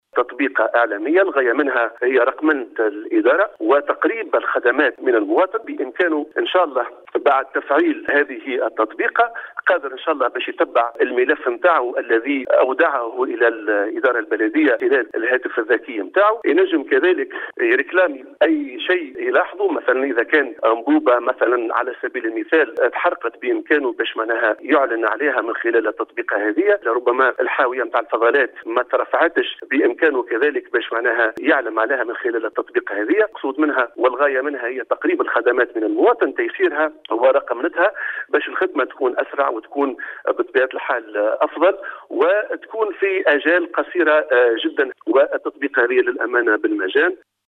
اكد رئيس بلدية طوزة من ولاية المنستير فؤاد الفرجاني اليوم الاثنين 22مارس 2021 في تصريح للاخبارانه تم تركيز المنصة الرقمية الخاصة بالبلدية من اجل احداث قاعدة معلوماتية لتسهيل التواصل الرقمي بين البلديات والمواطنين عبر مجموعة من الخدمات البلدية والتشاركية.